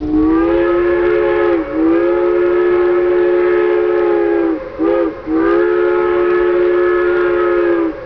Trainstop
TrainStop.wav